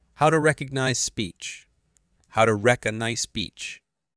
I’m turning text into speech.
That’s my voice.